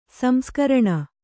♪ samskaraṇa